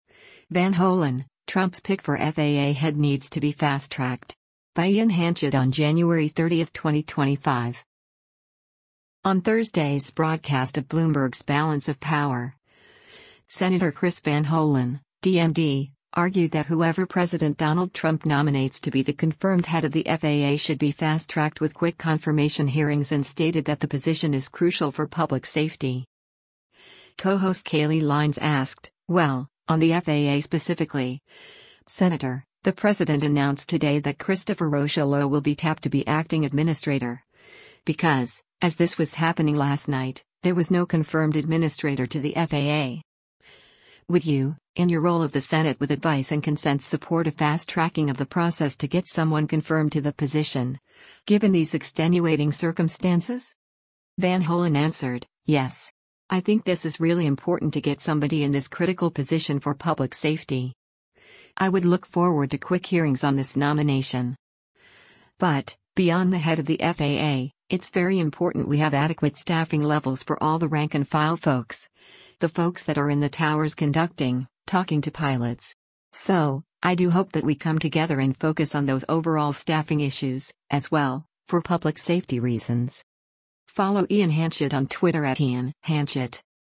On Thursday’s broadcast of Bloomberg’s “Balance of Power,” Sen. Chris Van Hollen (D-MD) argued that whoever President Donald Trump nominates to be the confirmed head of the FAA should be fast-tracked with quick confirmation hearings and stated that the position is crucial for public safety.